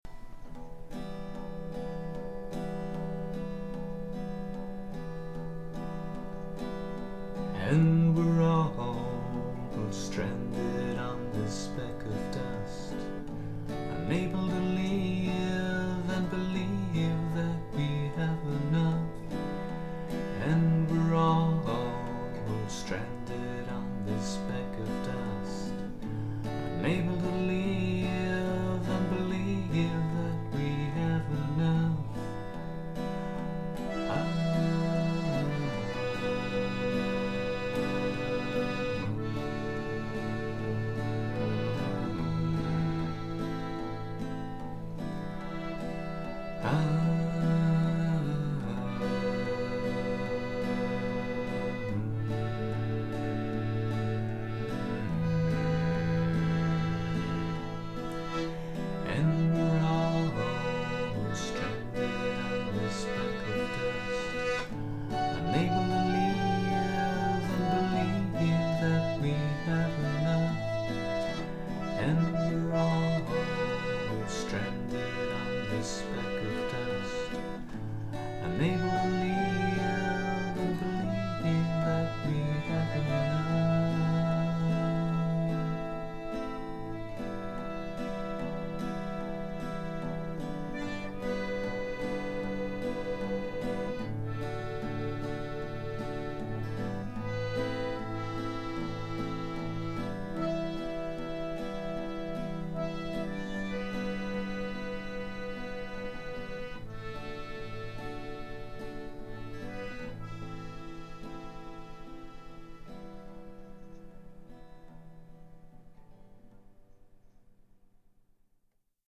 Nüüd paar mantra moodi laulu, mis on võrdlemisi vanad, kuid tuleb välja, et mõttelõkkesse lisamata. Olin toona just akordioni õnnelikuks omanikuks saanud ning tahtsin proovida, kuidas teda kitarrimänguga siduda.